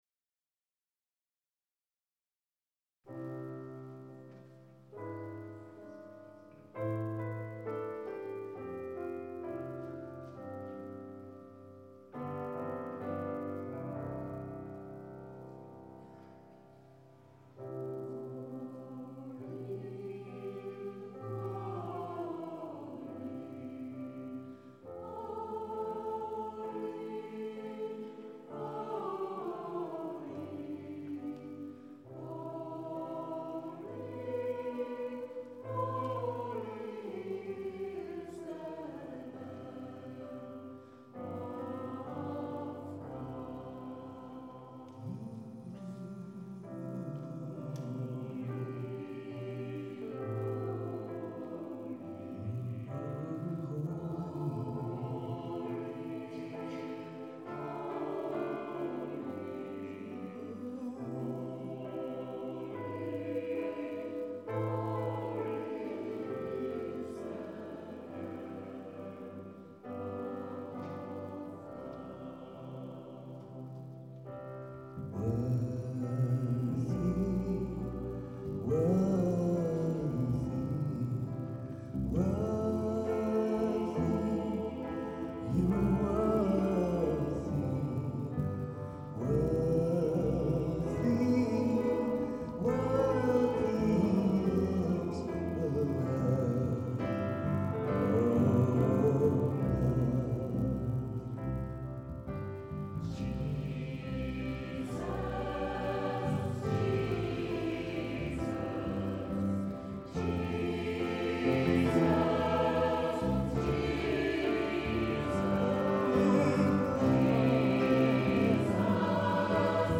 Gospelkonzert